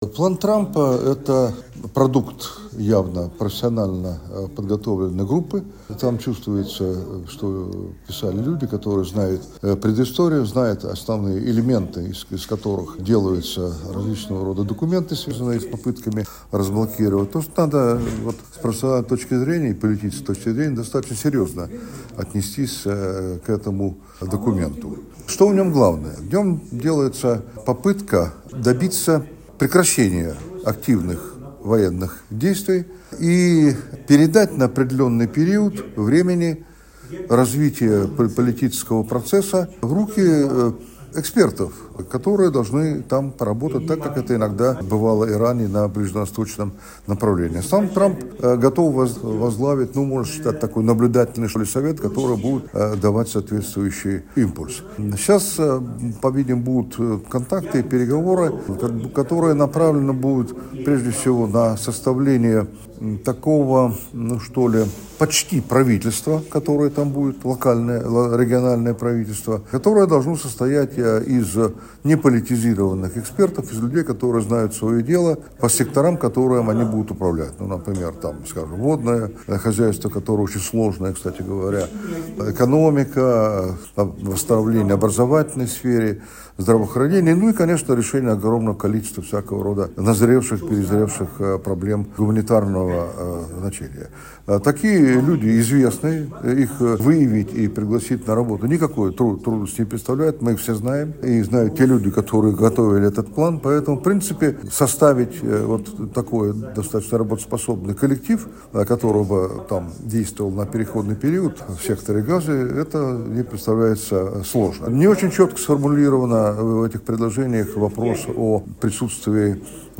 в интервью журналу «Международная жизнь» рассказал о мирном плане Дональда Трампа: